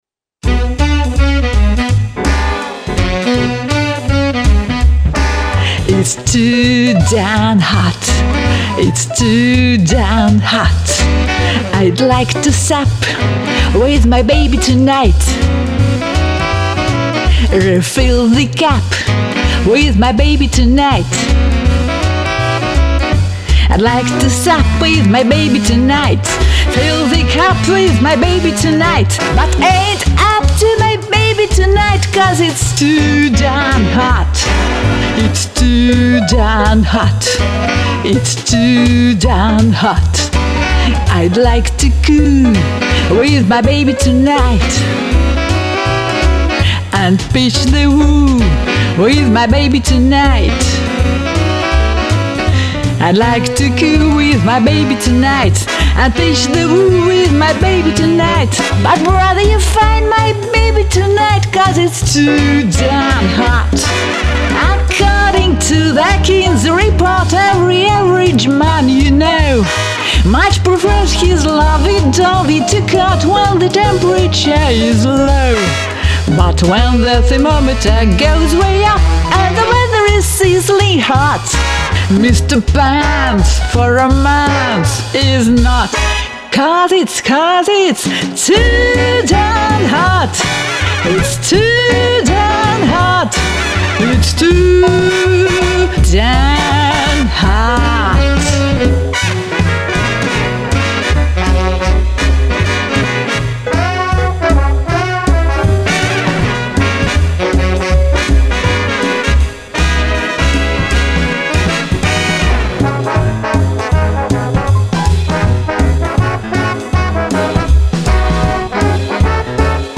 Джазовая классика!